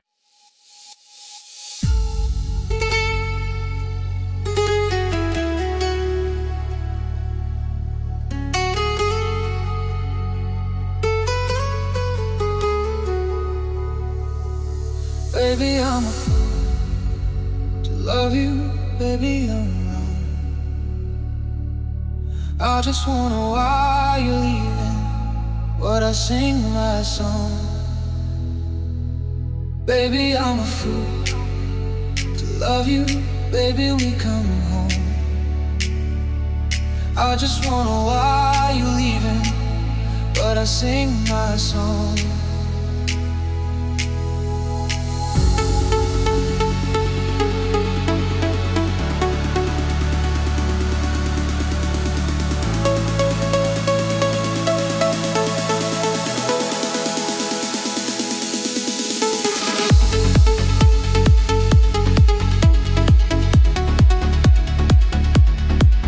der in der elektronischen Musik unterwegs ist.